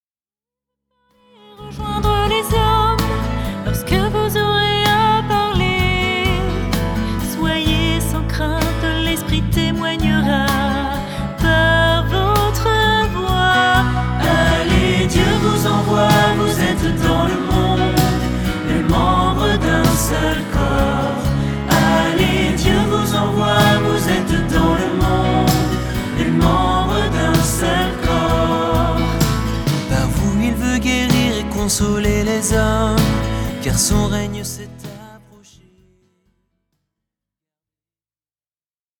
Célèbres chants de Louange